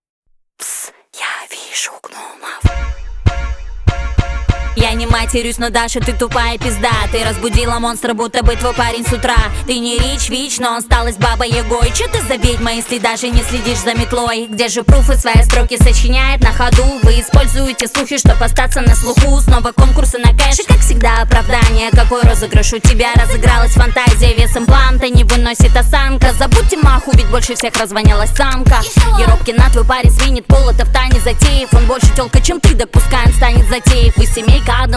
Rap Hip-Hop Rap
Жанр: Хип-Хоп / Рэп / Украинские